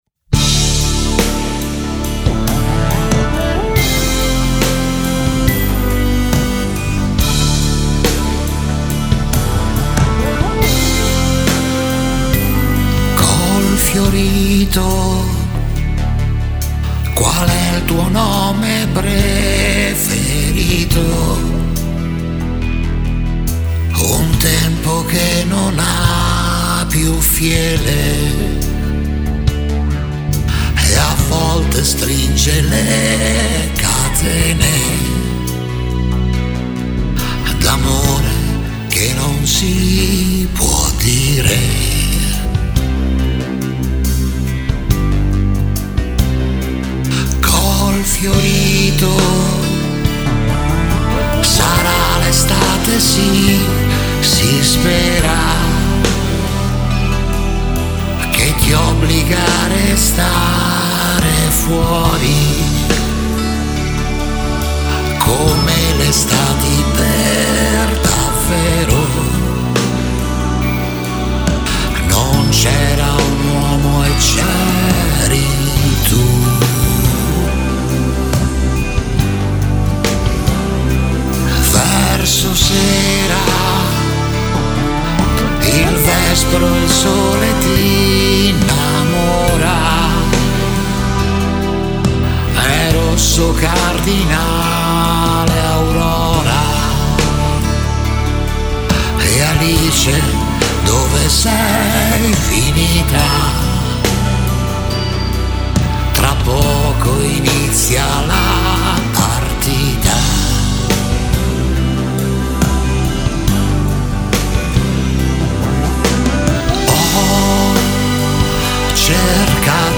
chitarra
tastiere
basso
batteria